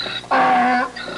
Donkey Call (short) Sound Effect
Download a high-quality donkey call (short) sound effect.
donkey-call-short.mp3